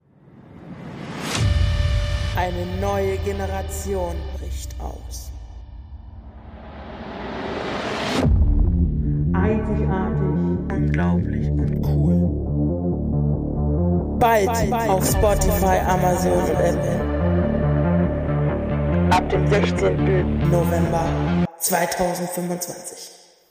Für Staffel 1 gibt es leider nicht das beste Equipment. Daher kann es möglich sein das die Audiodatei etwas Herausfordernd ist. Wir bitten daher um Entschuldigung und Verständnis.